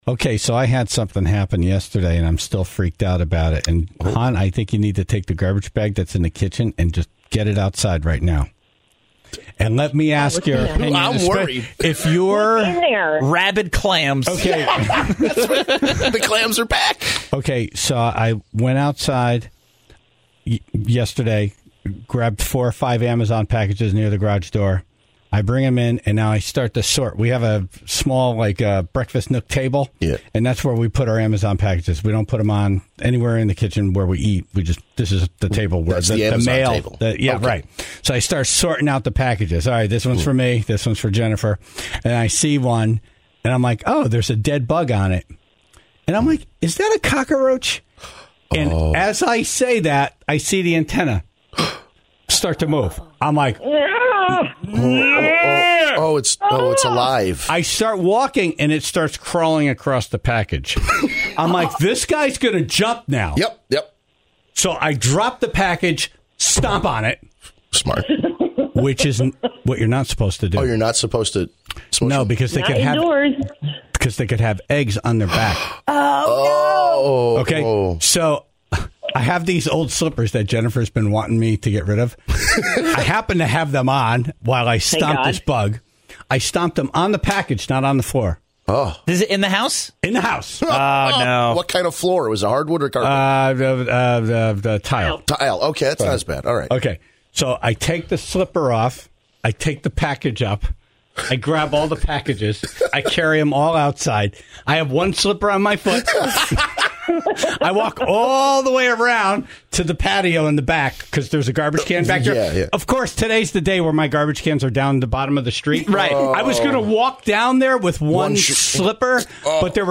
(0:00) Wallingford Mayor Vinny Cervoni was on the phone to share why a few principals will be kissing pigs this week. (6:53) In Dumb Ass News, we all learned about the hilarity that is Cat-aoke. Instead of singing lyrics, you just meow like a cat to the music.